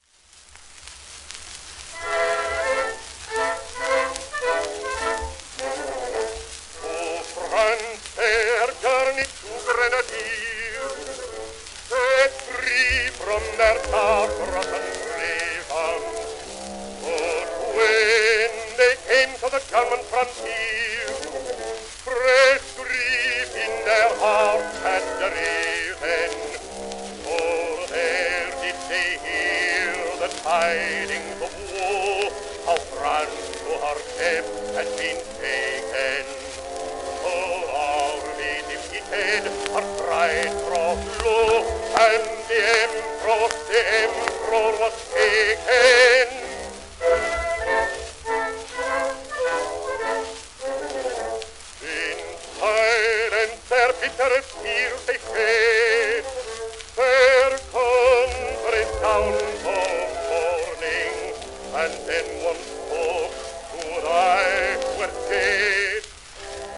デイヴィッド・ビスファム(Br:1857-1921)
w/オーケストラ
アメリカのバリトン歌手、1910年代の録音
旧 旧吹込みの略、電気録音以前の機械式録音盤（ラッパ吹込み）
DavidBispham_schumann_op49-1.m4a